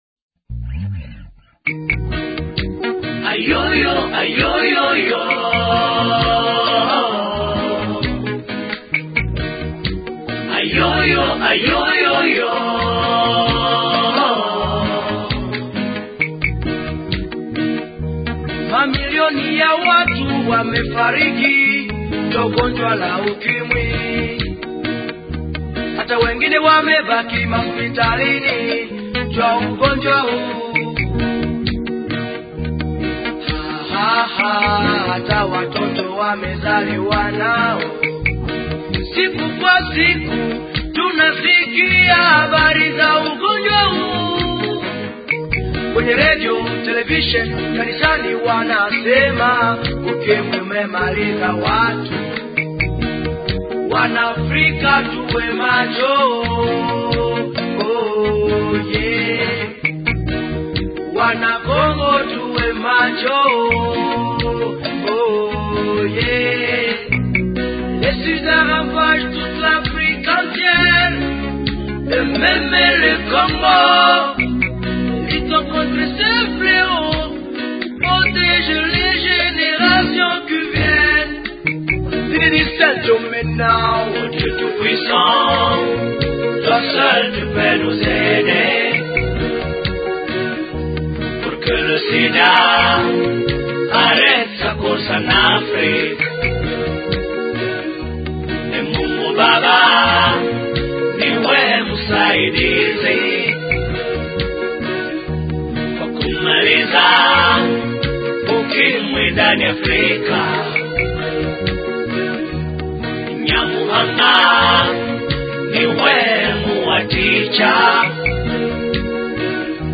musique congolaise